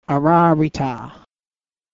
• Pronunciation Note: I have included sound files to show you how these words of power are pronounced.